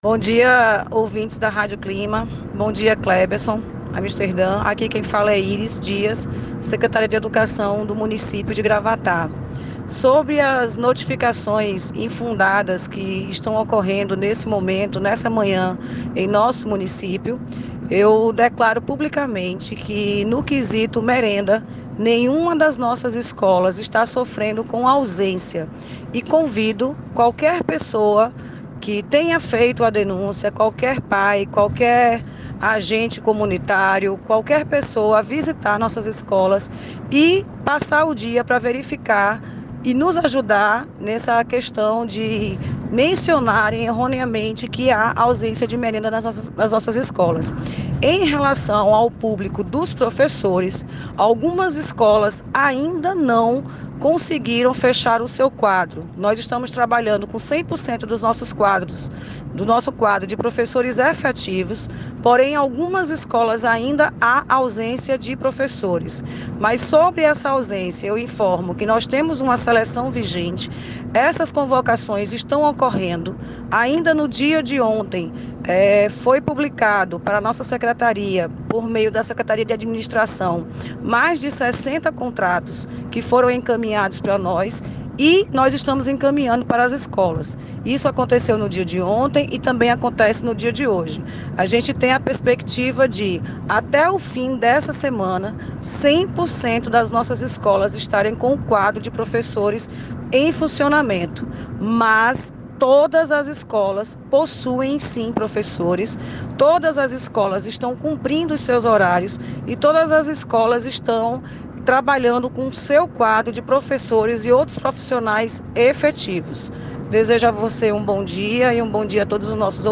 Em nota de voz enviada a produção do site, a secretária informou que não procede a informação da falta de merenda nas escolas.